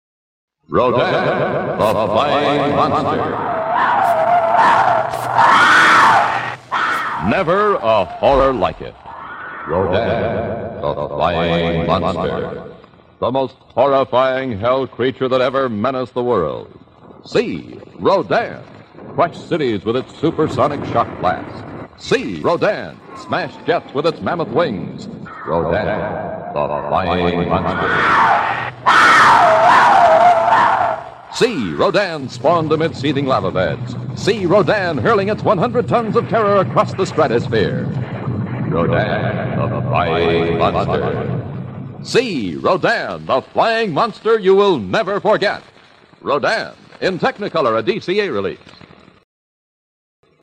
The Flying Monster Radio Spots
20, 30, and 60 seconds radio spots for Rodan!